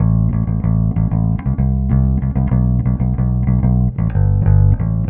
Trem Trance Bass 02a.wav